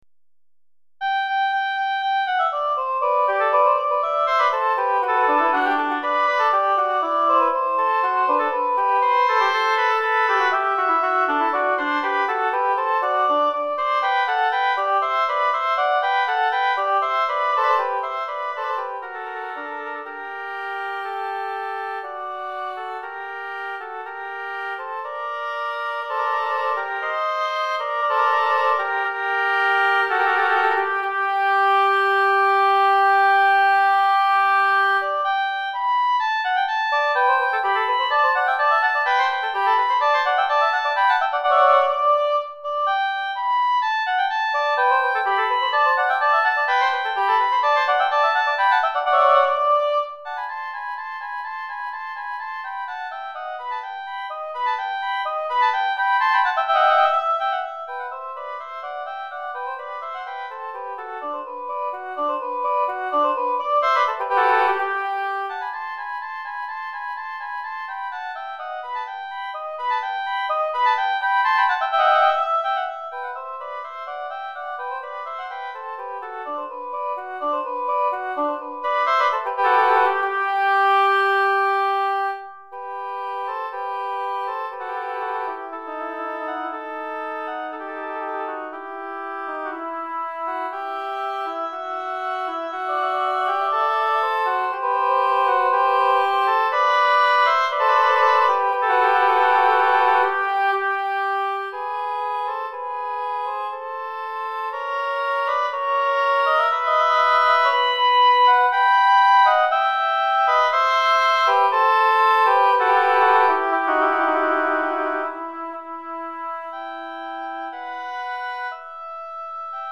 2 Hautbois